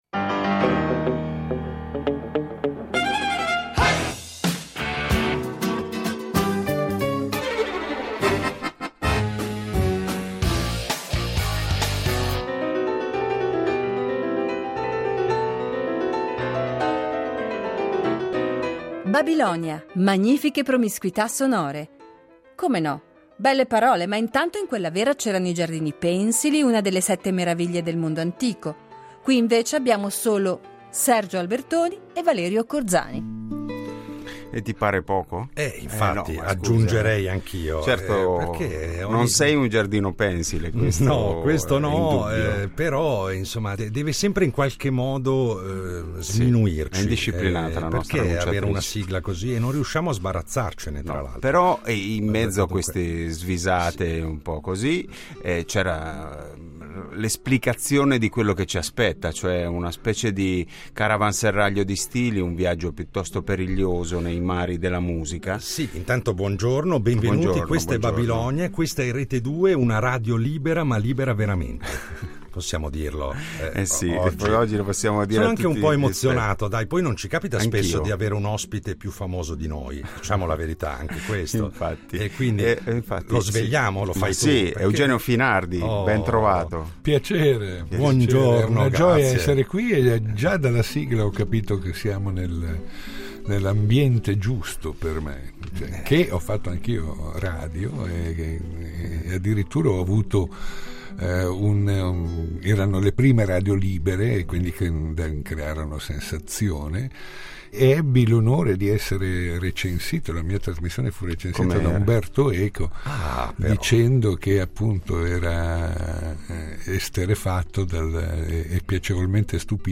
Le promiscuità sonore che animano i nostri percorsi musicali sono il propellente perfetto per alimentare le rare doti di affabulatore di Eugenio Finardi , che tra un ascolto e l'altro troverà mille spunti anche inattesi per raccontare momenti della sua lunga carriera, sensazioni, emozioni del passato e del presente.